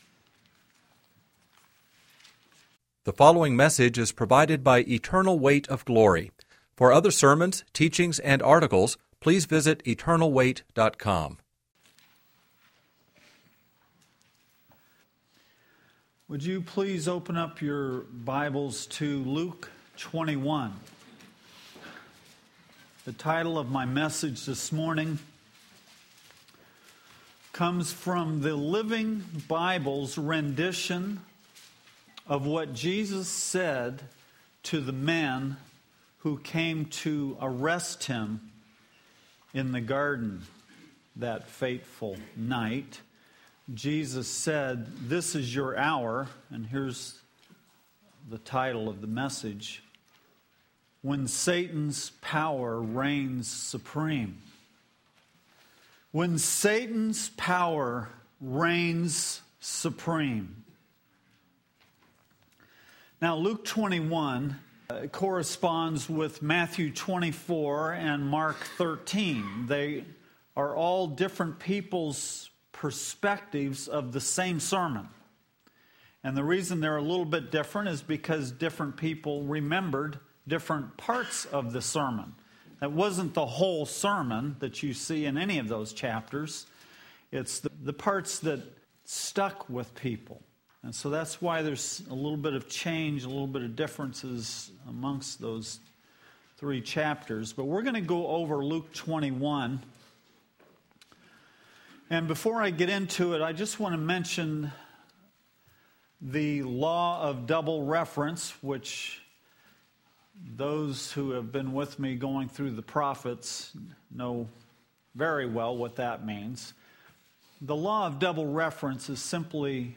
In this sermon, the preacher discusses the concept of Satan's power reigning supreme in the world. He believes that we are currently entering a dark period where the enemy is being allowed greater power on Earth.